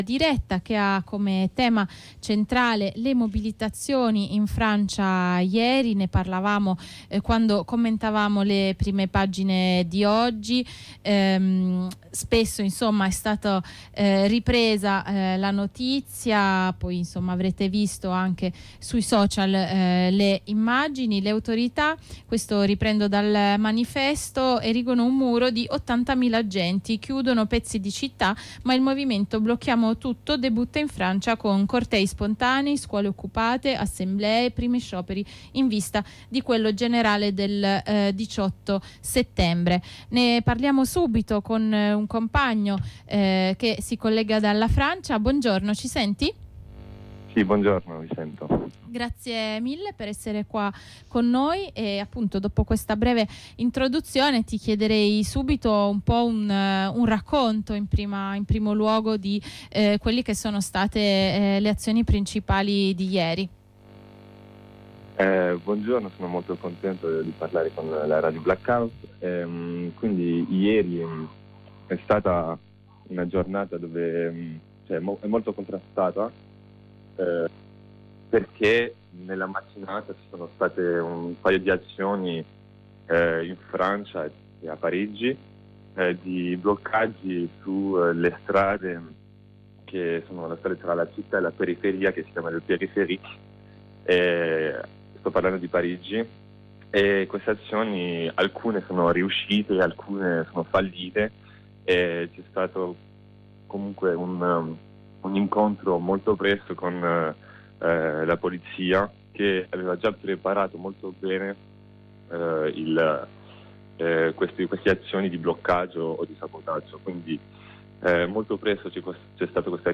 Un compagno francese ci restituisce una prima valutazione a caldo di questi giorni.